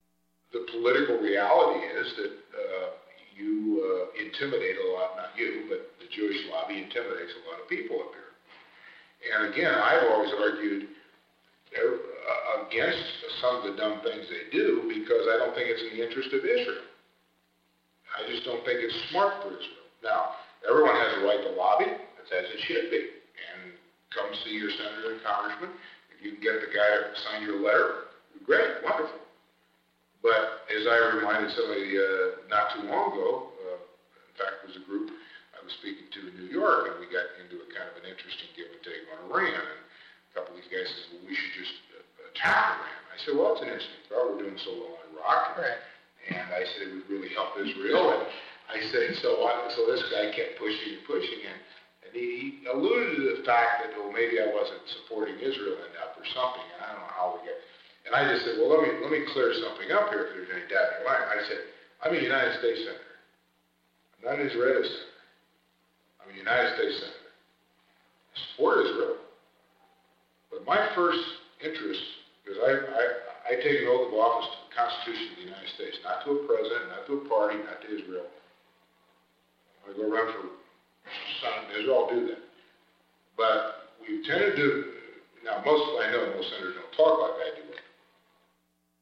In 2006, Middle East scholar and policy advisor Aaron David Miller interviewed Hagel for a book he was writing.